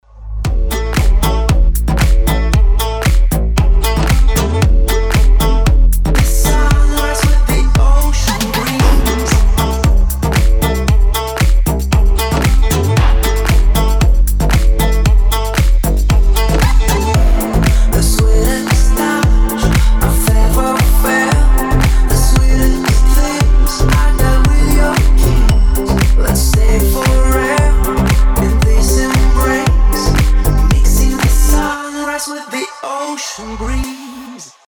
гитара
красивые
женский вокал
deep house
летние
Основной стиль: deep house.